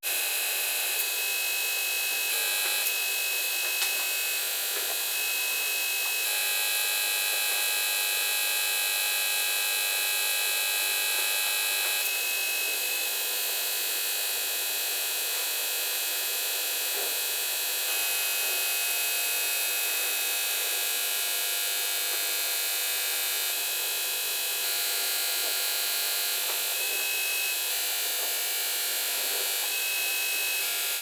Electricidad estática de una televisión de plasma 02
televisión
electricidad estática
Sonidos: Hogar